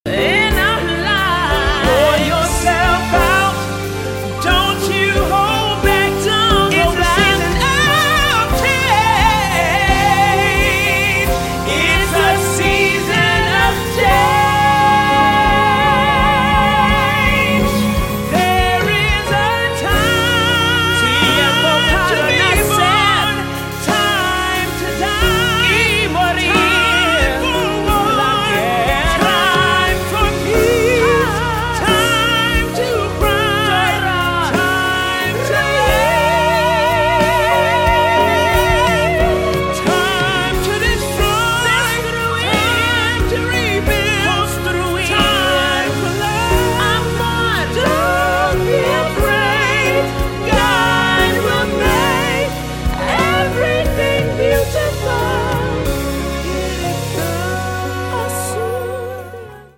✅ Opera sound effects free download